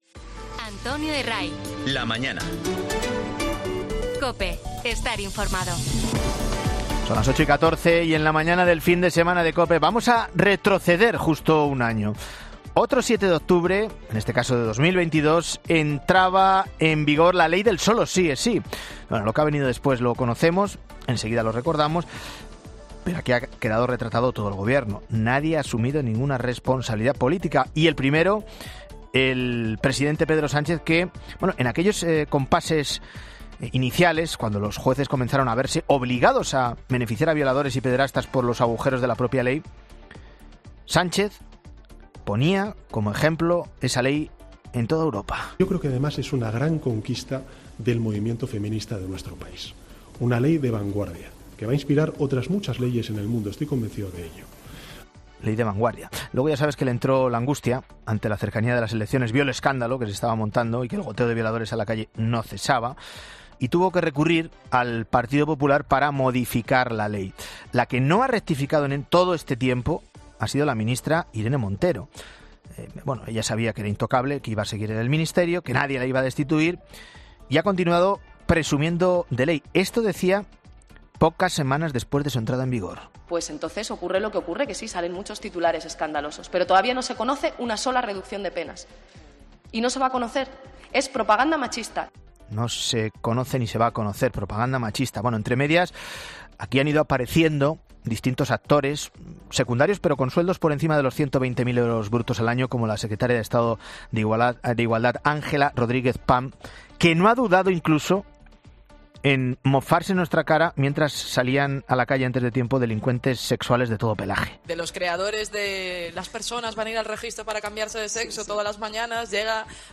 Una magistrada hace balance en COPE de la ley del Sí es Sí un año después de su aprobación